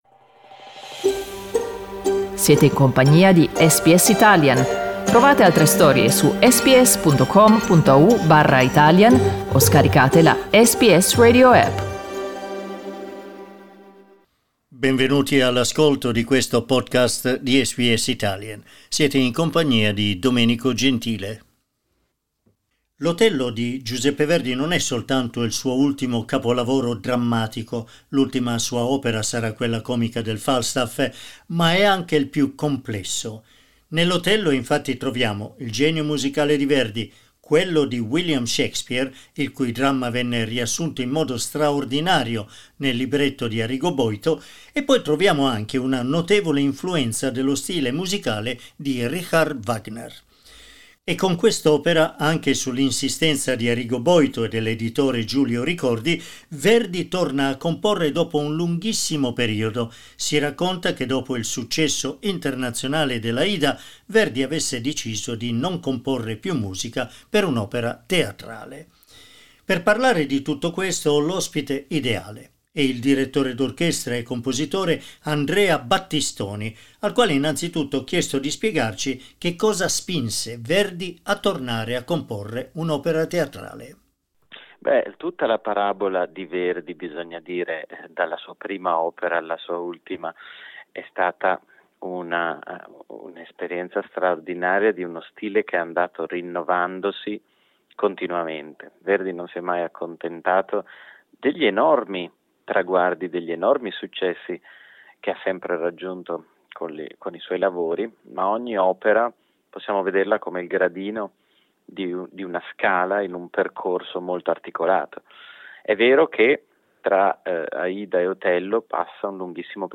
In questa conversazione Battistoni parla del ruolo determinante di Arrigo Boito (compositore anche lui) nel convincere Verdi a tornare a comporre musica per il teatro e soprattutto per essere riuscito, in modo straordinario, a riassumere nel suo libretto l’anima del grande capolavoro di William Shakespeare.